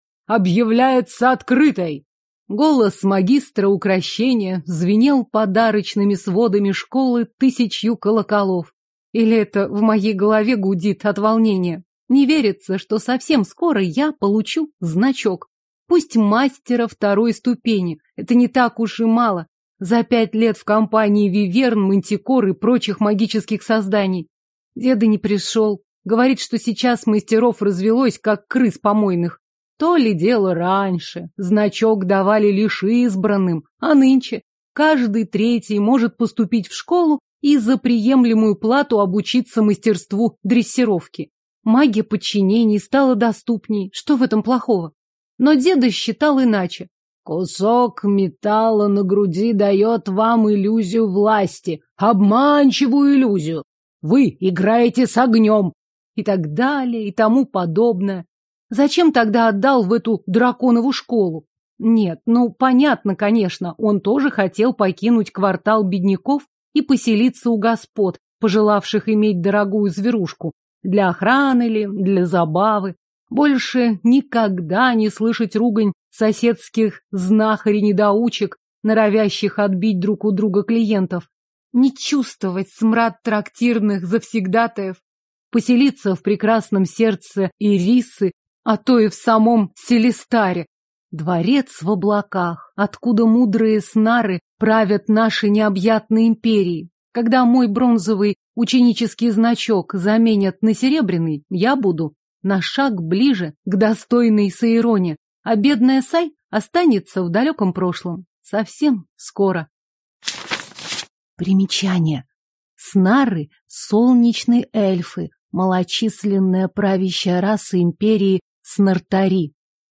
Аудиокнига Ядовитые узы, или Два зельевара – гремучая смесь | Библиотека аудиокниг